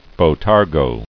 [bo·tar·go]